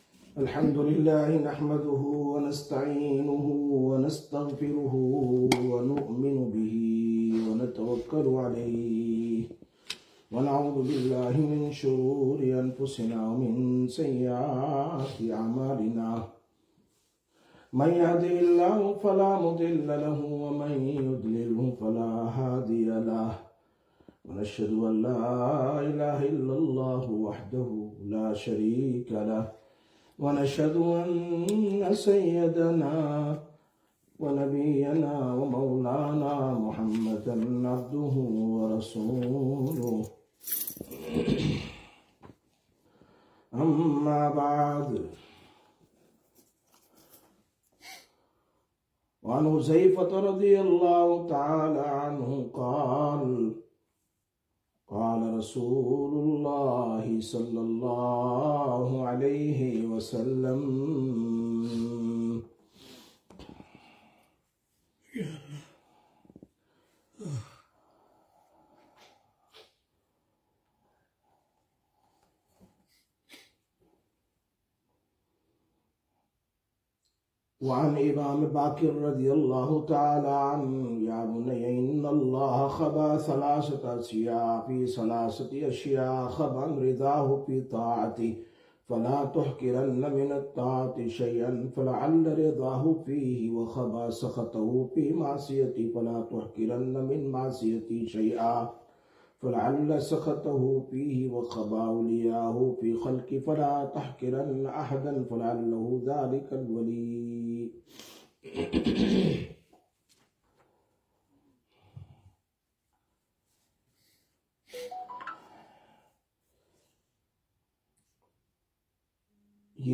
09/07/2025 Sisters Bayan, Masjid Quba